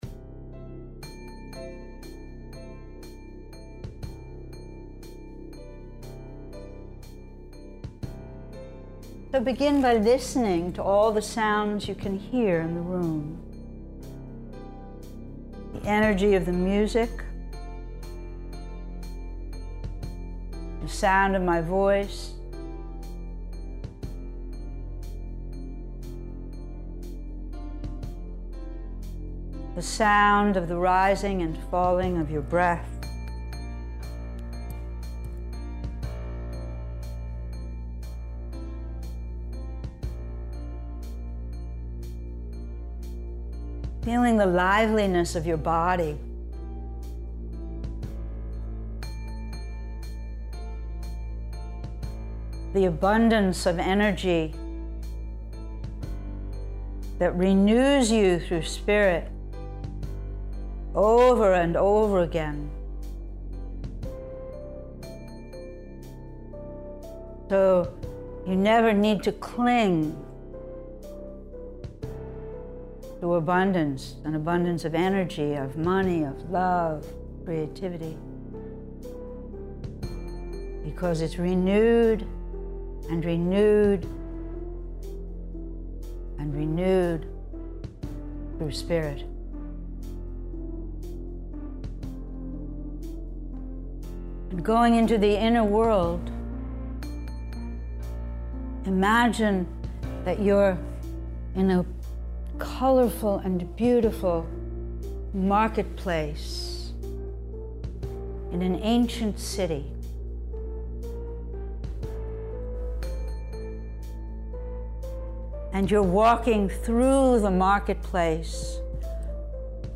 They are recorded live in class.